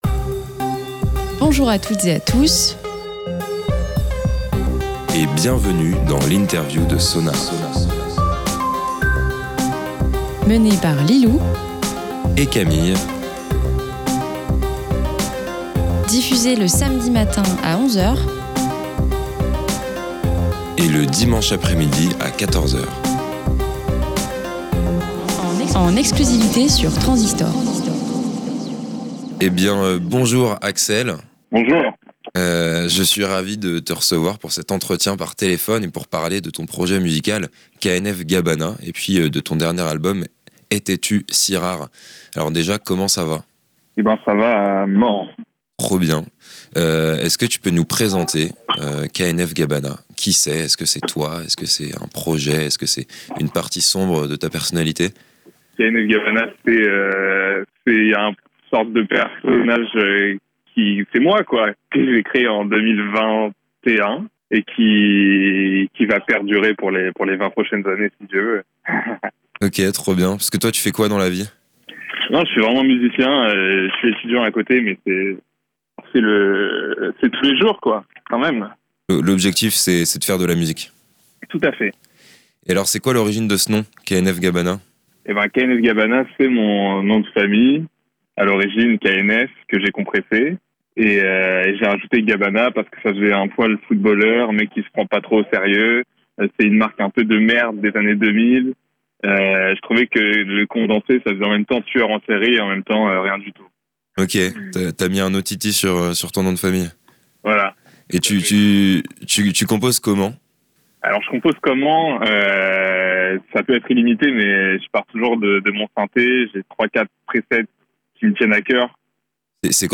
SONAR x INTERVIEW